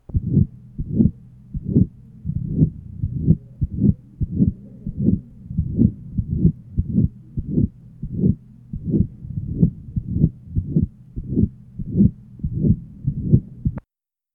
HeartSounds Project Audio Player
Date 1969 Type Unknown Abnormality Unknown Freq. Det. Off, recorded at level 5 To listen, click on the link below.